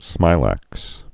(smīlăks)